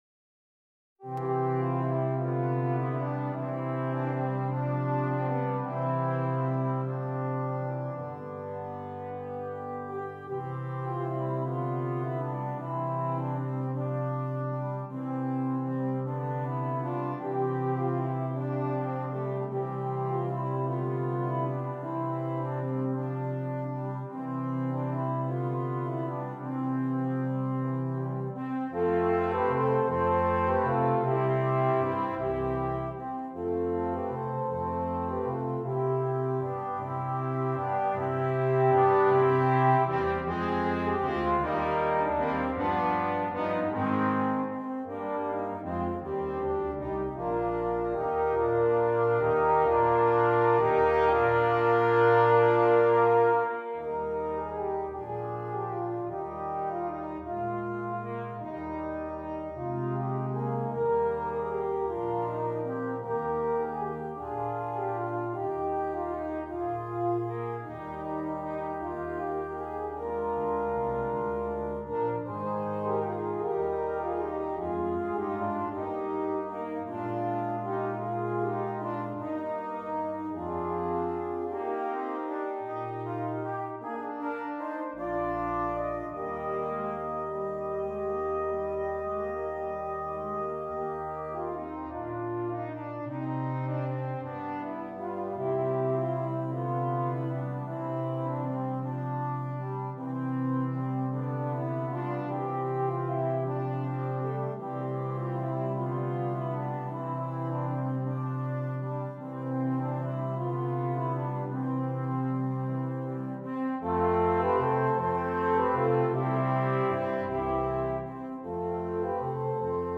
Brass Quintet
This beautiful and flowing Christmas carol